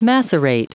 Prononciation du mot macerate en anglais (fichier audio)
Prononciation du mot : macerate